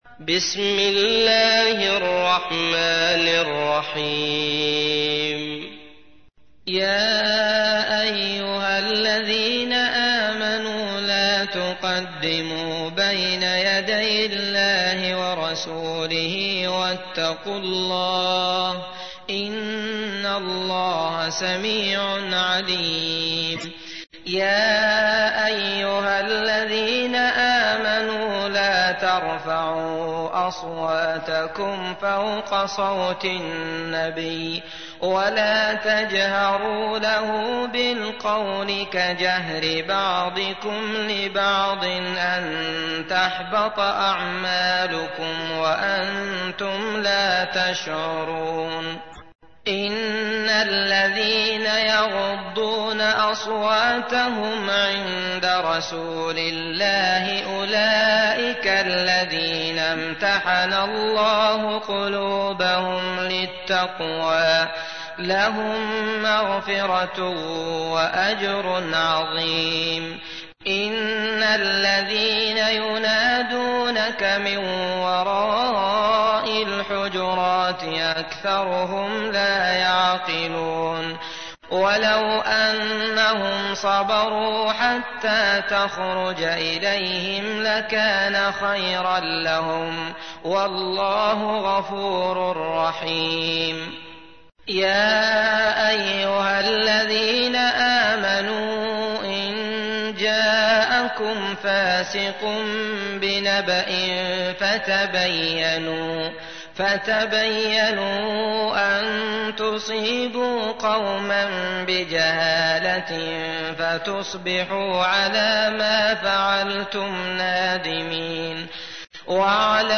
تحميل : 49. سورة الحجرات / القارئ عبد الله المطرود / القرآن الكريم / موقع يا حسين